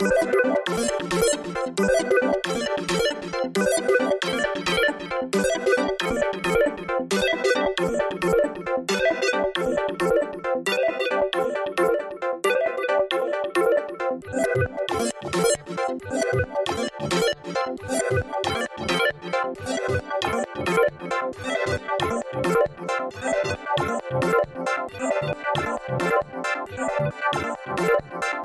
一个黑暗的俱乐部合成器循环
描述：一个合成器的黑暗循环
标签： 135 bpm Disco Loops Synth Loops 4.79 MB wav Key : Unknown
声道立体声